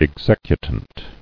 [ex·ec·u·tant]